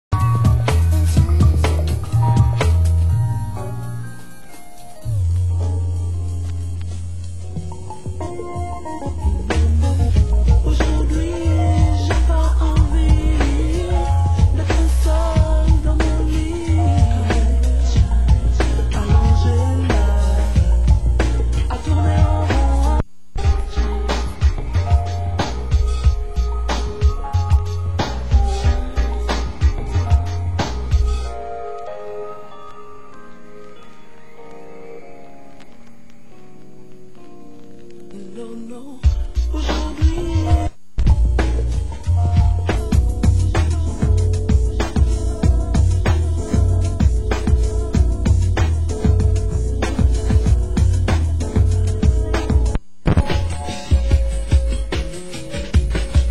Genre: Trip Hop